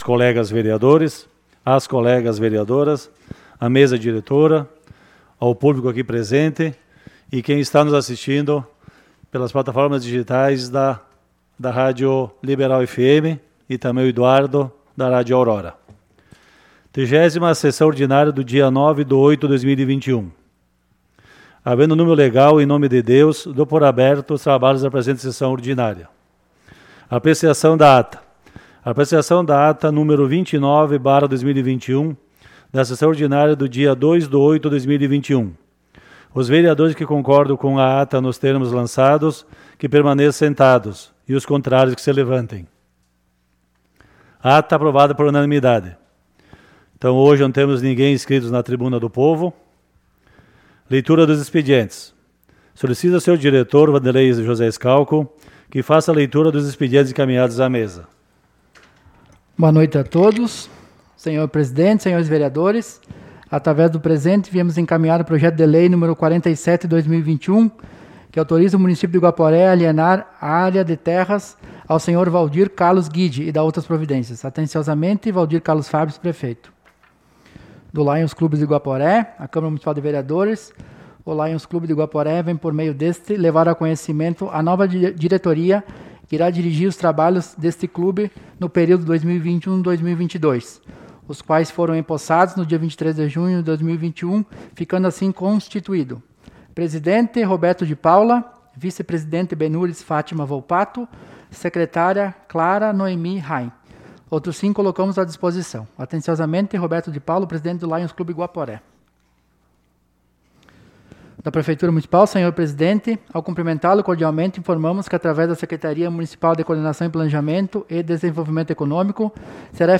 Sessão Ordinária